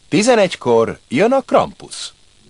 krampusz.wav